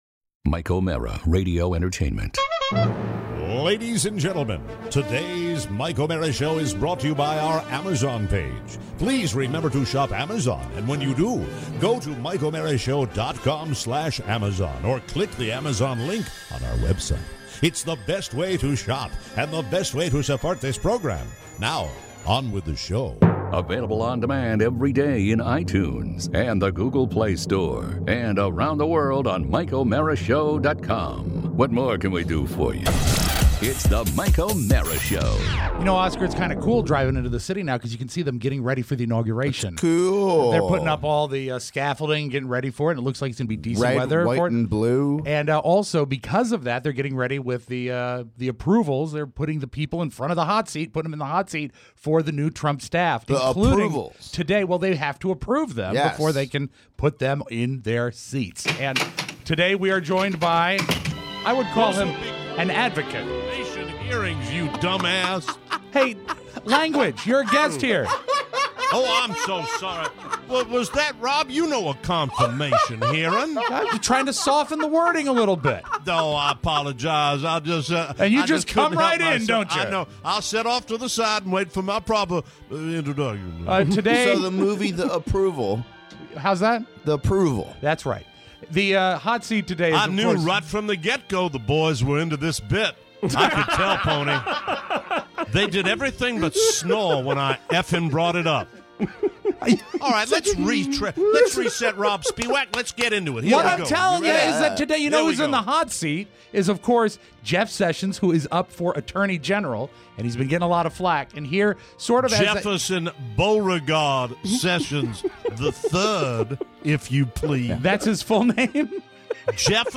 Today… we are joined in studio by a pilot! Plus, car fun… TV fun… football fun… and the Santa Joke.